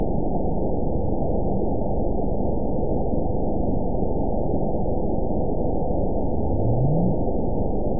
event 920357 date 03/18/24 time 05:21:05 GMT (1 year, 1 month ago) score 9.68 location TSS-AB01 detected by nrw target species NRW annotations +NRW Spectrogram: Frequency (kHz) vs. Time (s) audio not available .wav